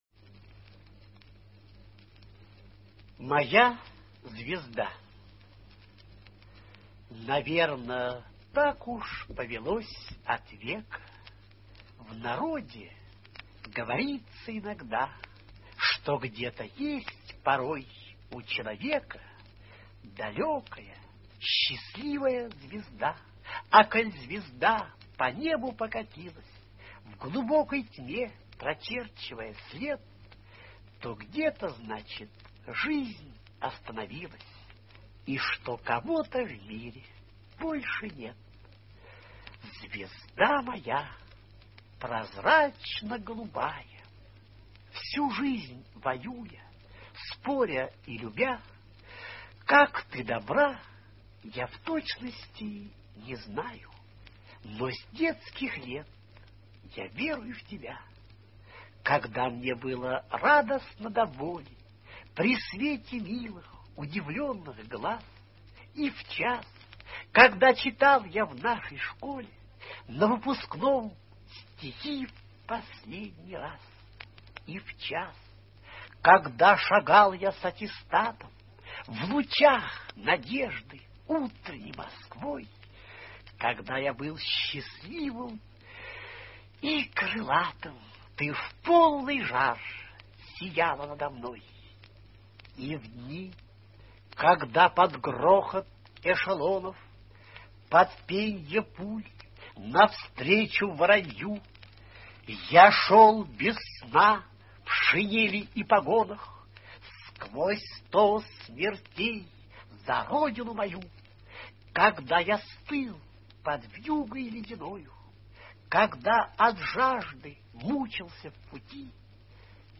eduard-asadov-moya-zvezda-chitaet-avtor